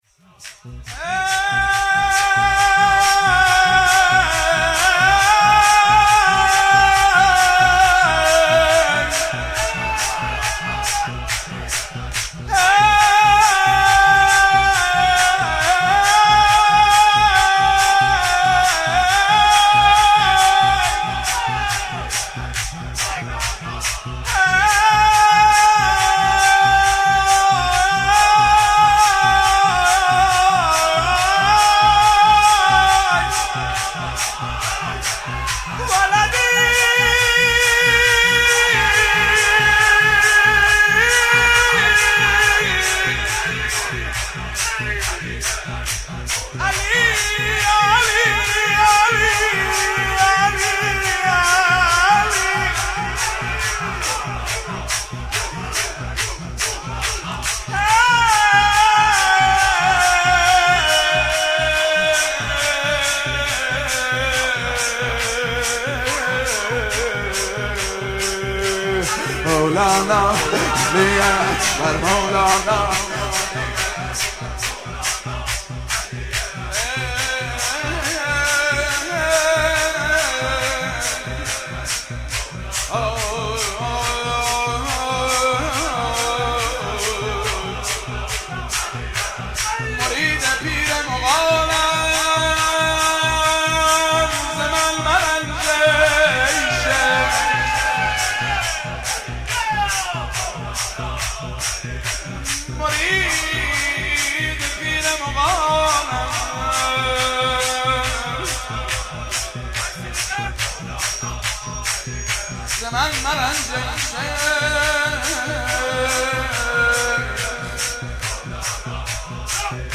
مناسبت : دهه اول صفر
قالب : نغمه